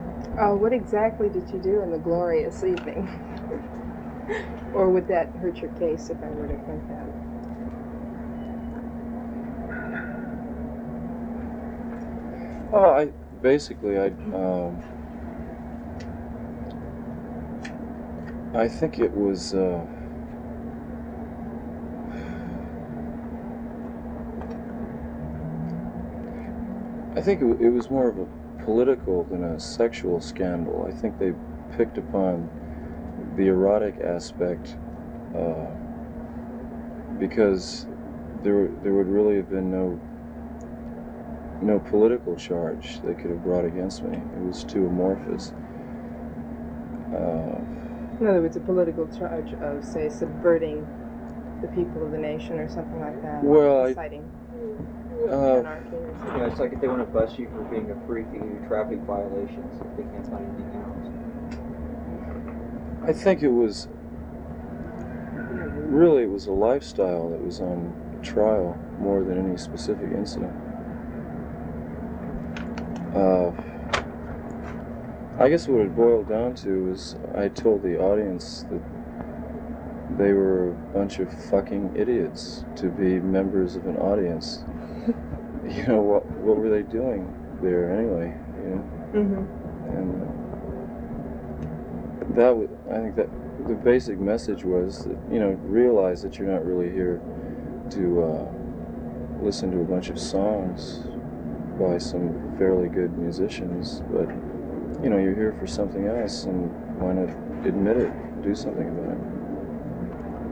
12 What Did You Do That Evening (The Lost Interview Tapes - Volume Two).flac